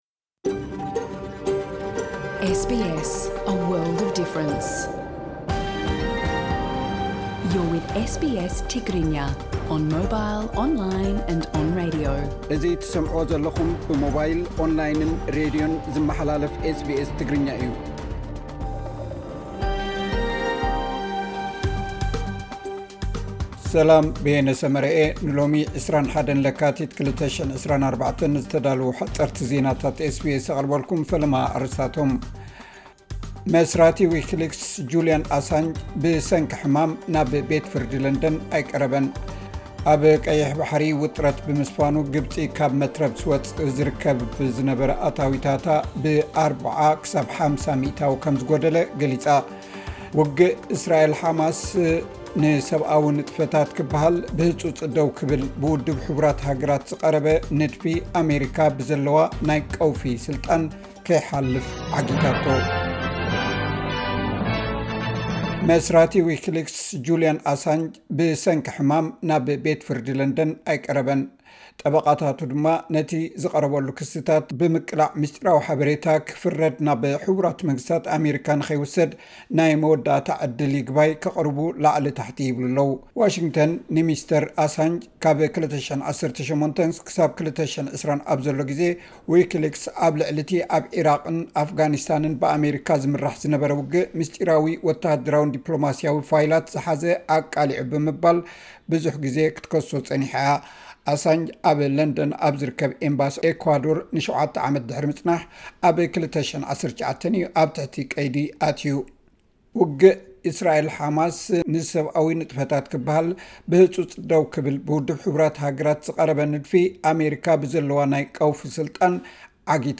ዉጥረት ኣብ ቀይሕ ባሕሪ ንኣታዊታት ግብጺ ይጸልዎ፡ ሓጸርቲ ዜናታት ኤስ ቢ ኤስ ትግርኛ (21 ለካቲት 2024)